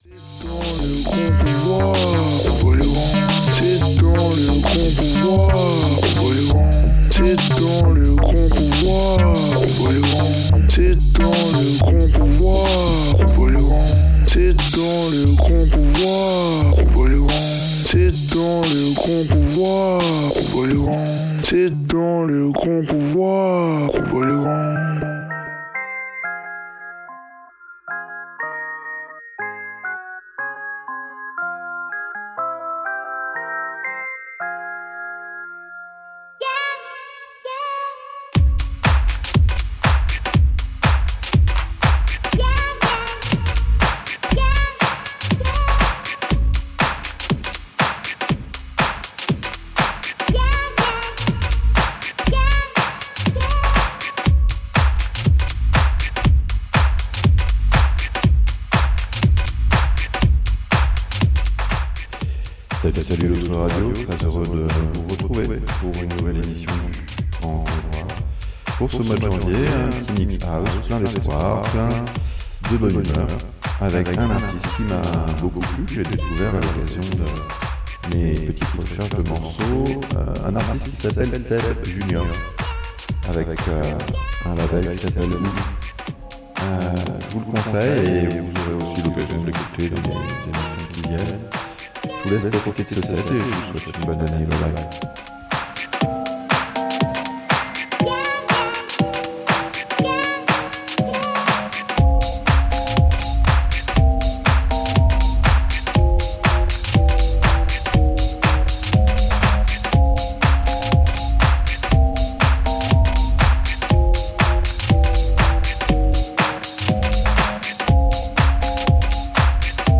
Un set mensuel d'une heure